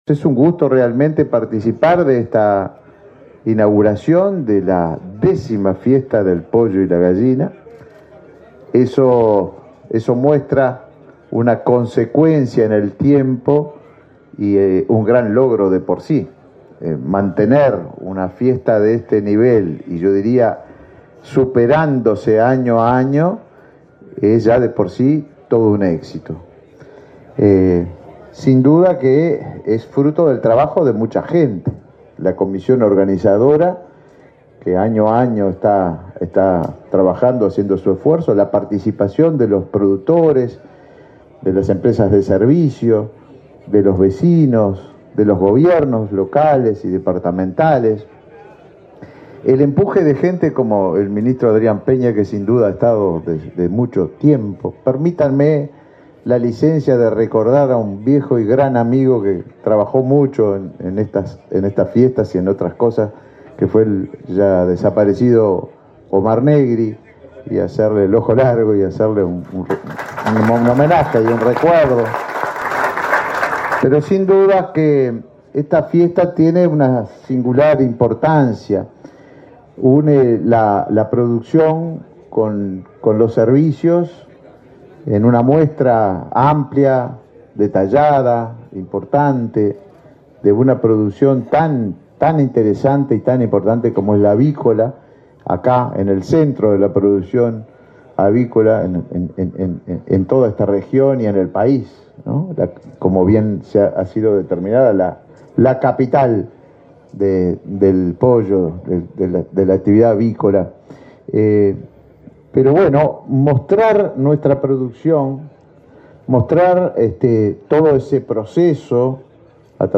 Inauguración Expo Avícola 2022
Los ministros de Turismo, Tabaré Viera, y Ganadería, Fernando Mattos, participaron en la inauguración de la Expo Avícola 2022, en San Bautista,